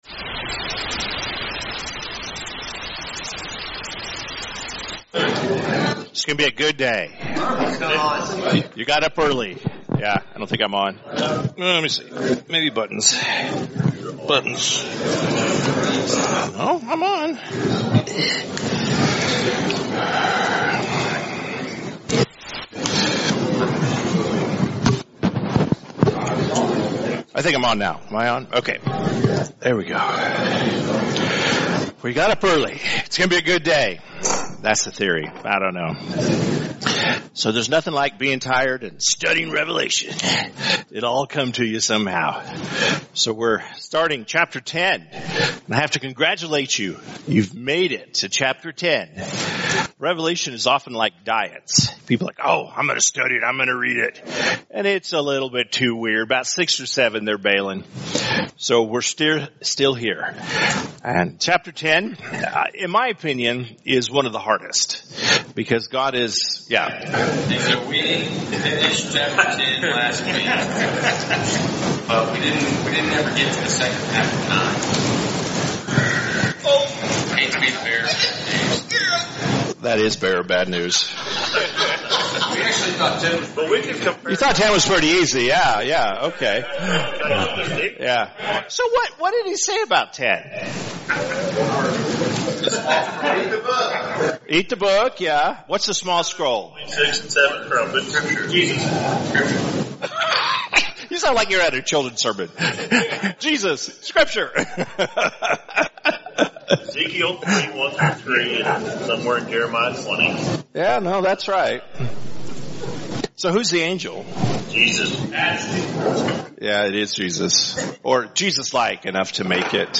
Great Sermon
Mens-Breakfast-Bible-Study-9.24.mp3